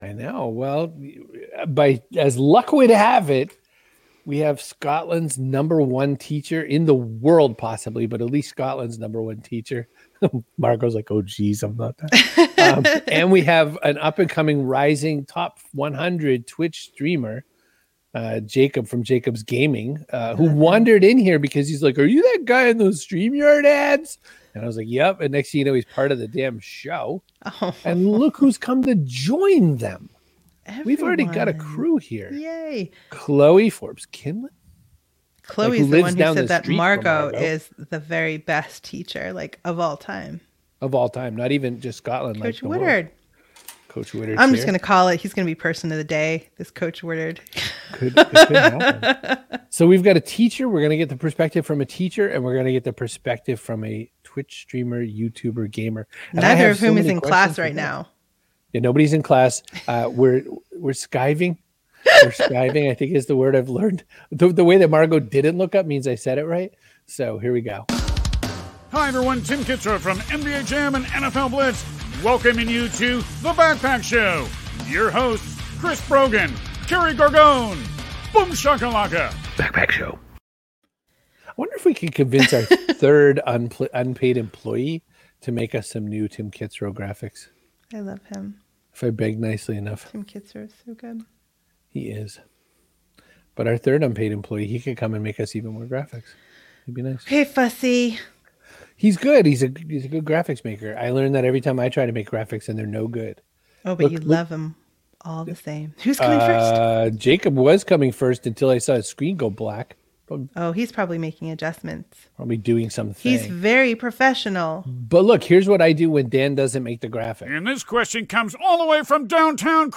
We loved him so much, we invited him back for a proper interview!